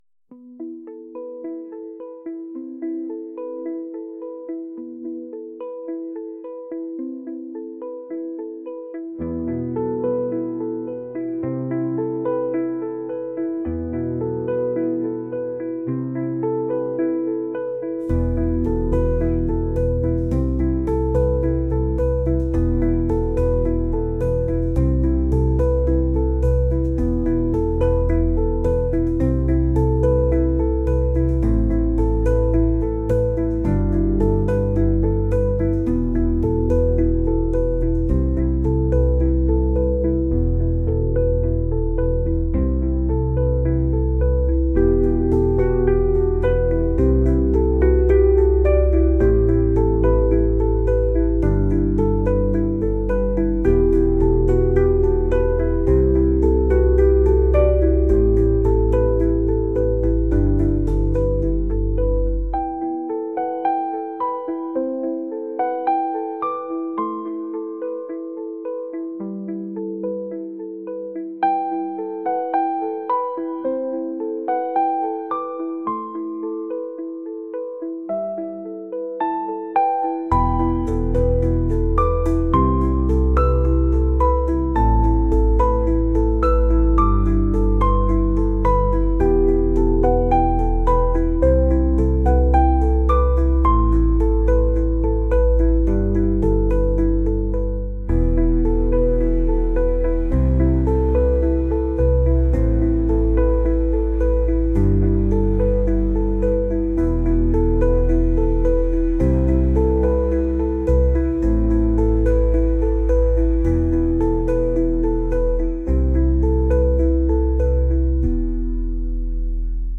pop | ambient | electronic